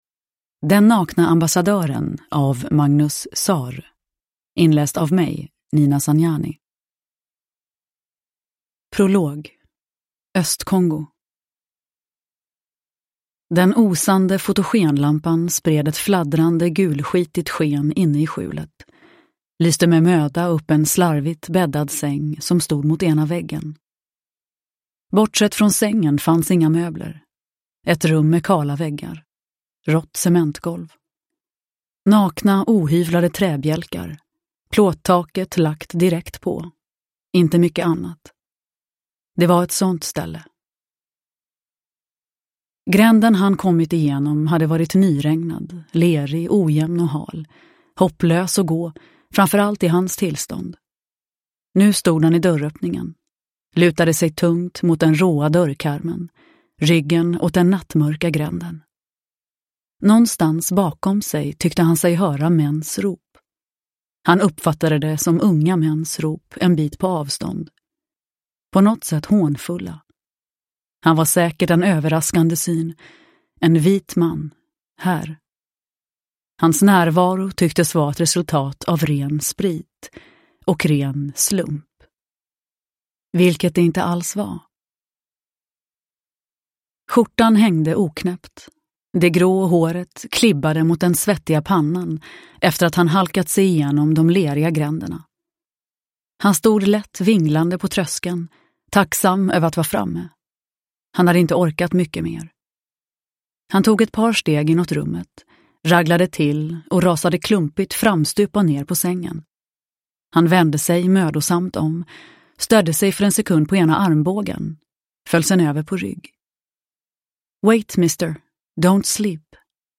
Den nakne ambassadören – Ljudbok – Laddas ner
Uppläsare: Nina Zanjani